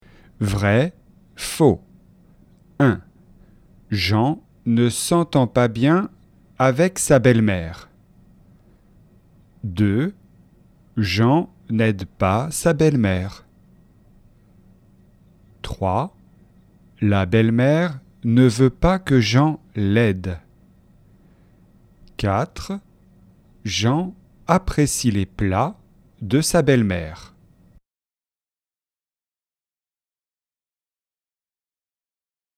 Repas avec la belle-mère - dialogue en français facile